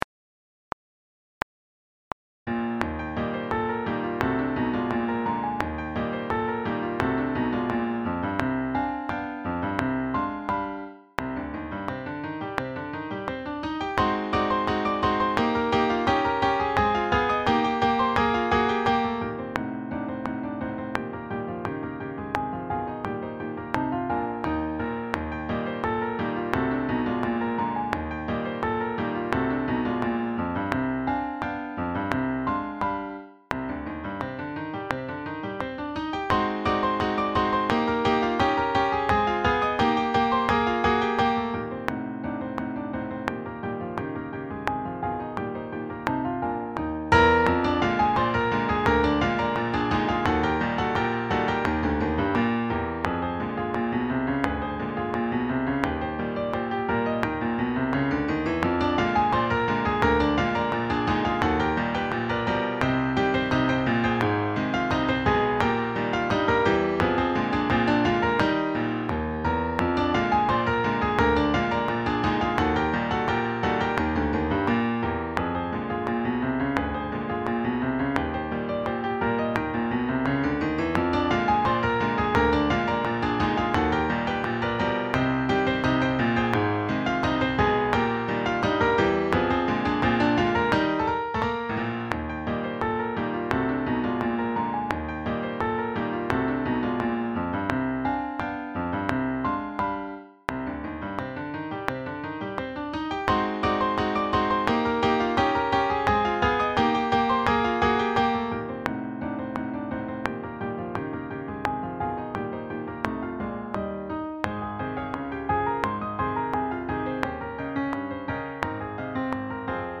Sax Sextets
sSATBbDuration:
Backing track